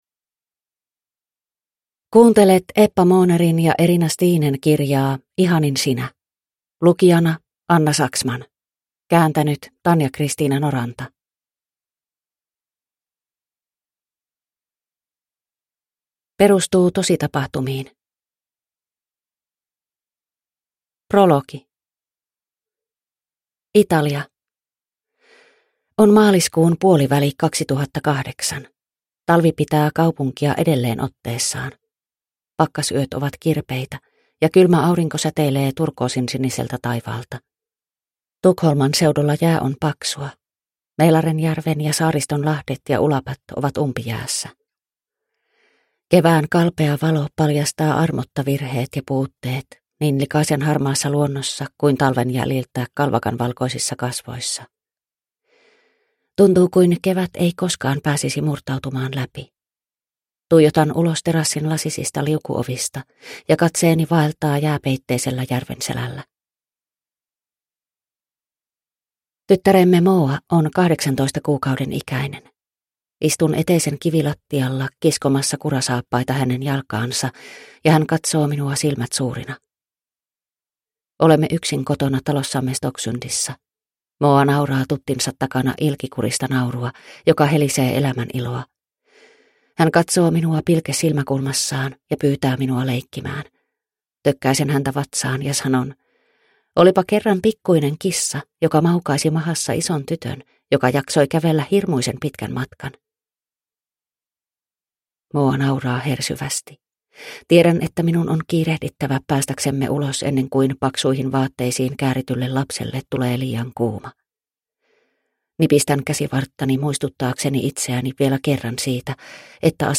Ihanin sinä – Ljudbok – Laddas ner